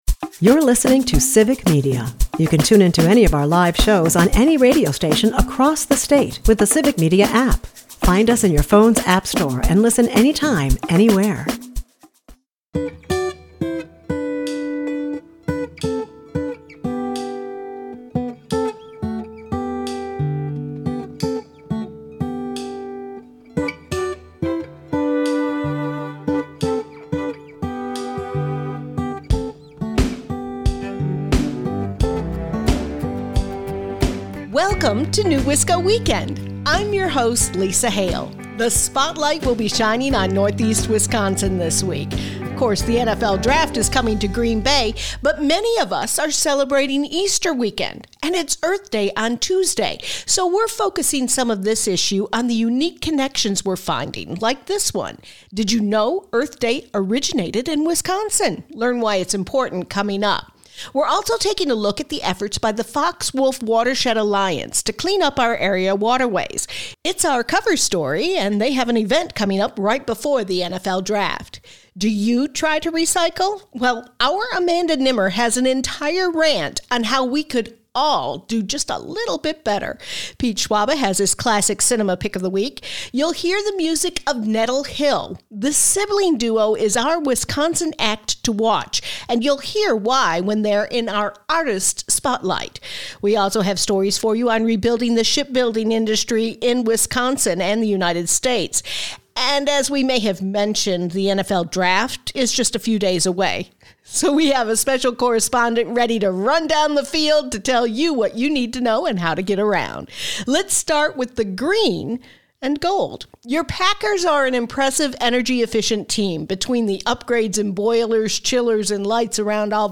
Nettle Hill is the musical guest.